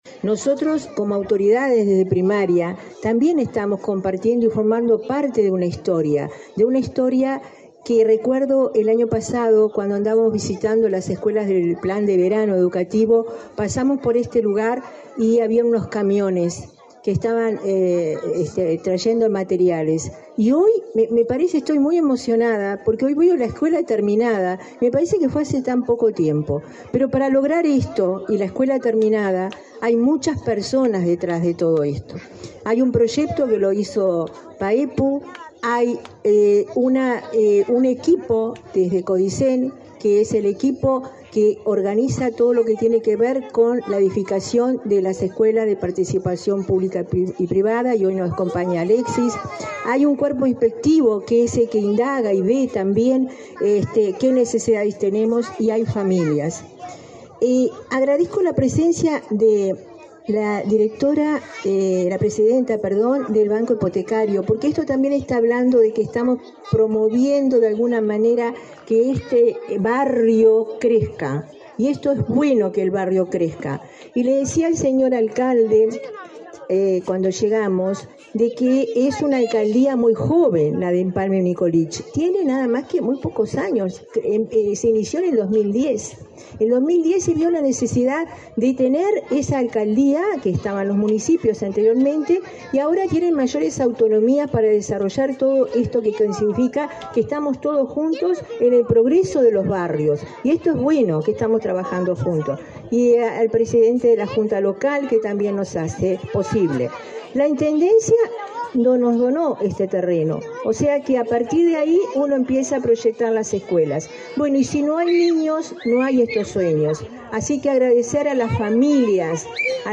Palabras de autoridades de la ANEP en Canelones
Palabras de autoridades de la ANEP en Canelones 27/09/2024 Compartir Facebook X Copiar enlace WhatsApp LinkedIn Este viernes 27, la directora general de Educación Primaria, Olga de las Heras, y la presidenta de la Administración Nacional de Educación Pública (ANEP), Virginia Cáceres, participaron en la inauguración de la escuela n.° 319, de tiempo completo, en Empalme Nicolich, departamento de Canelones.